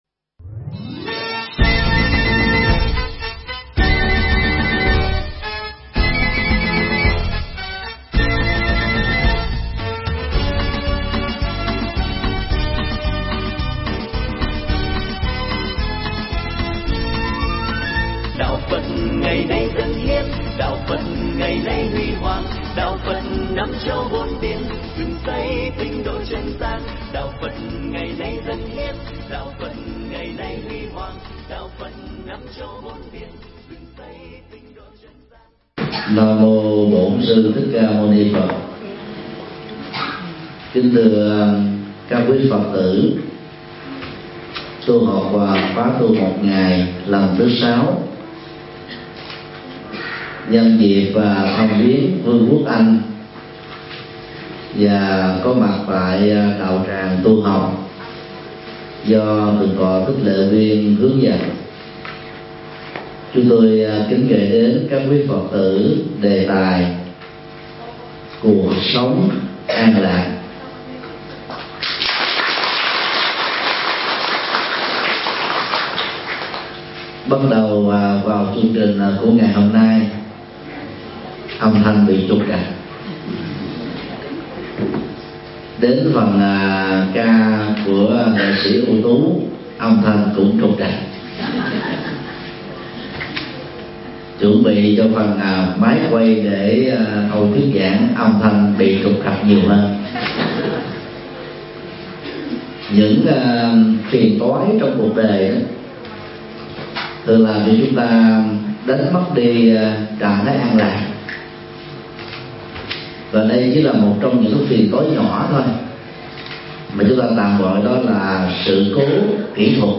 Tải mp3 Pháp Thoại Cuộc sống an lạc – Thầy Thích Nhật Từ Giảng tại London, Anh quốc, ngày 10 tháng 1 năm 2016